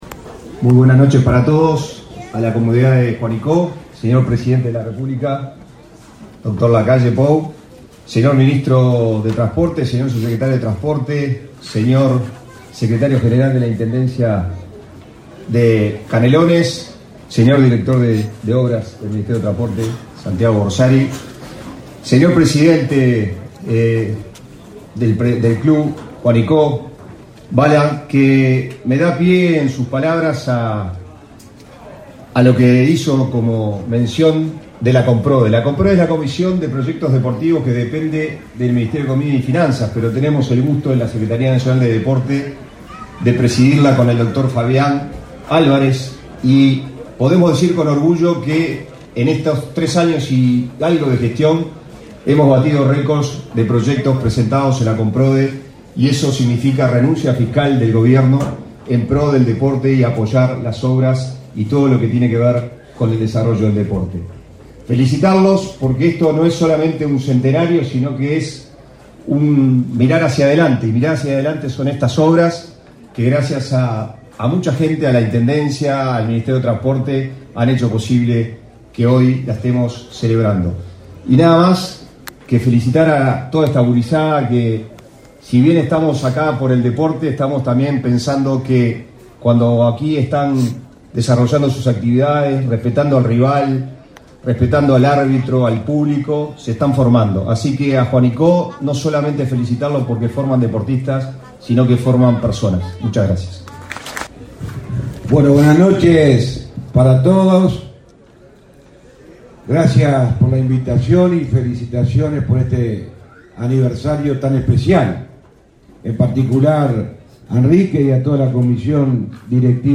Acto por la inauguración del estadio Gloria de un Pueblo, del Club Atlético Juanicó, en Canelones
Con la presencia del presidente de la República, Luis Lacalle Pou, fue inaugurado el estadio Gloria de un Pueblo, del Club Atlético Juanicó, en Canelones, este 12 de octubre. Participaron en el evento el ministro de Transporte y Obras Públicas, José Luis Falero, y el subsecretario nacional del Deporte, Pablo Ferrari.